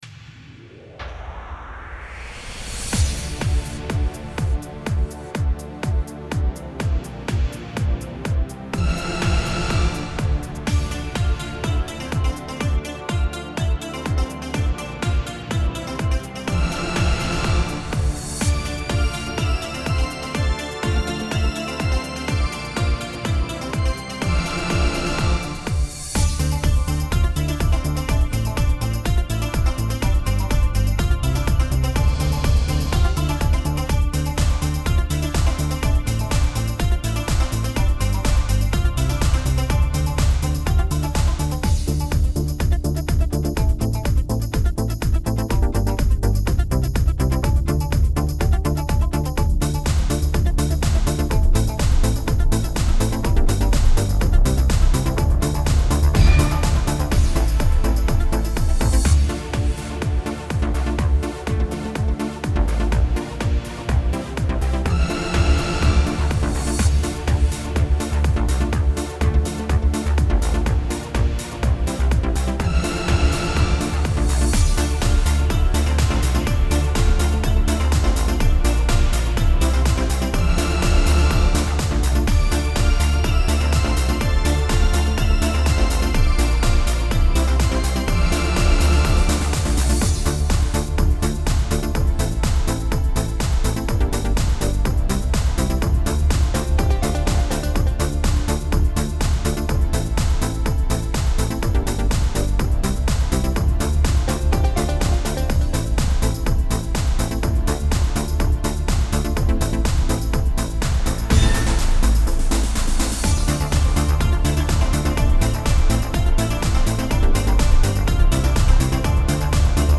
минусовка версия 71329